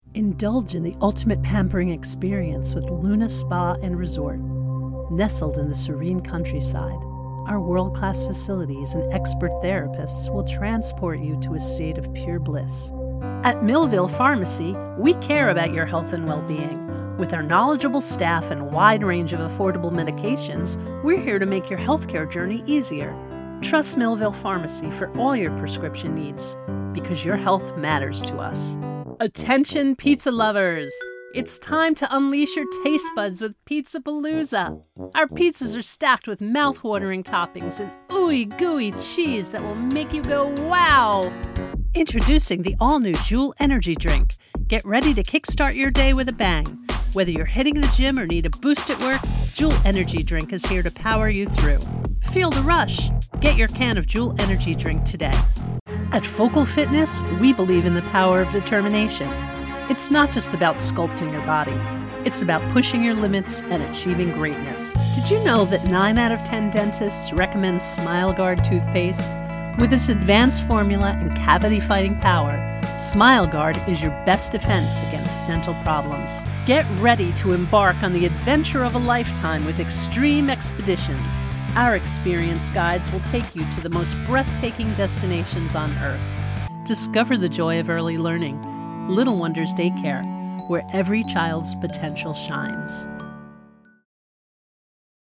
VoiceOver Demo Reel